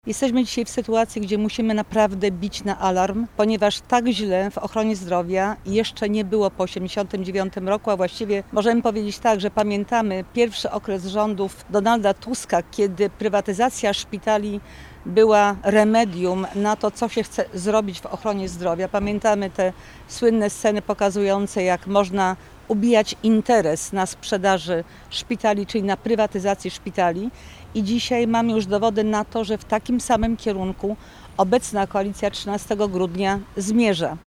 Wiceprezes PiS Elżbieta Witek, a także posłowie Paweł Hreniak, Jacek Świat oraz radni Sejmiku Województwa Dolnośląskiego spotkali się przy szpitalu uniwersyteckim we Wrocławiu, by zaapelować do rządu o podjęcie działań ws. ochrony i służby zdrowia.